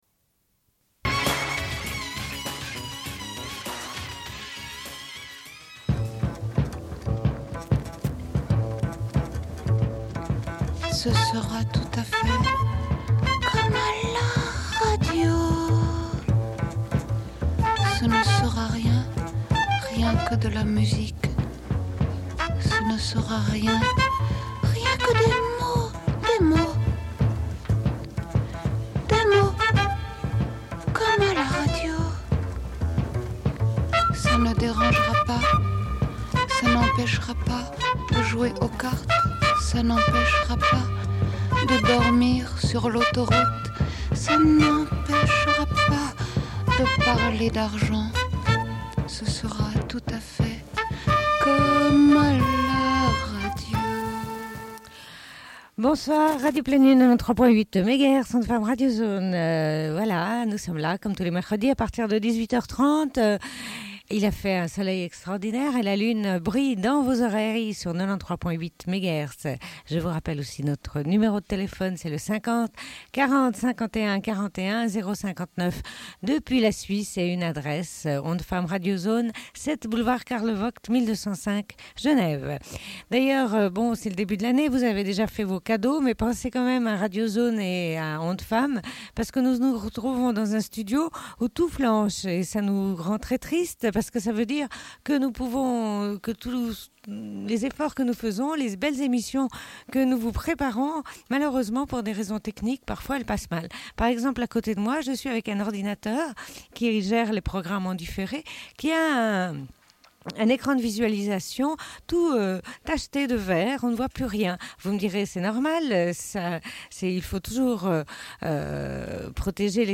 Bulletin d'information de Radio Pleine Lune du 18.02.1998 - Archives contestataires
Une cassette audio, face B